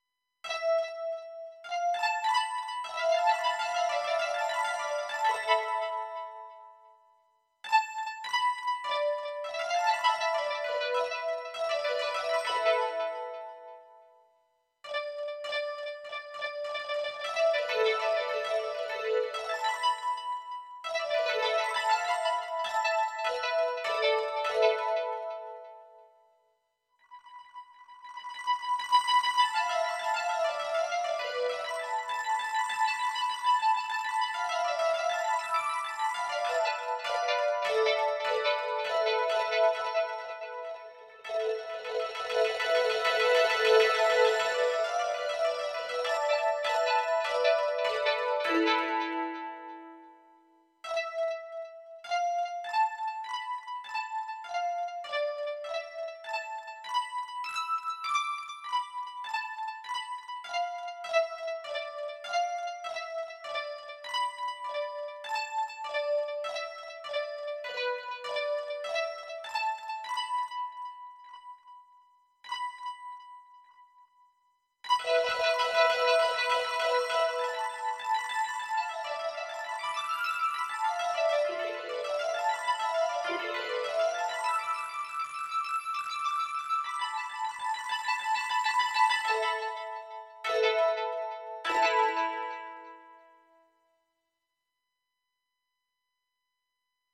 お正月な感じの和風曲です。